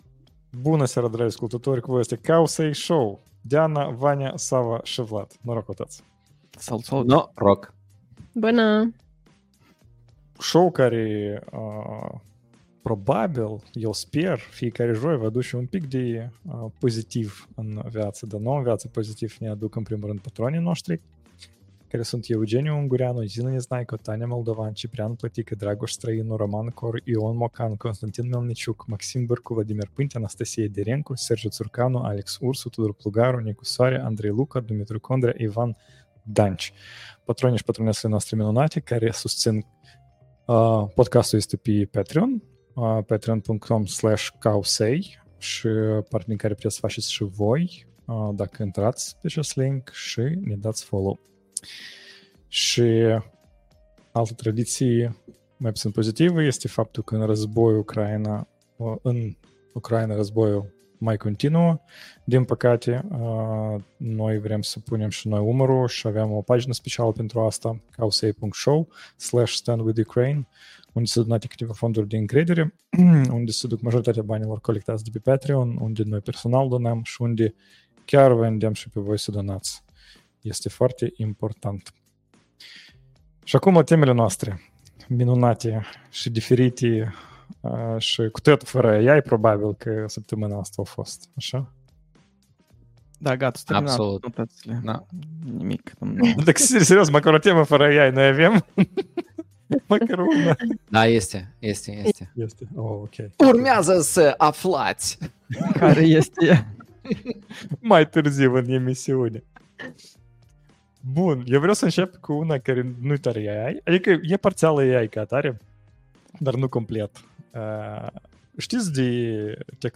September 26th, 2024 Live-ul săptămânal Cowsay Show.